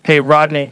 synthetic-wakewords
ovos-tts-plugin-deepponies_Joe Rogan_en.wav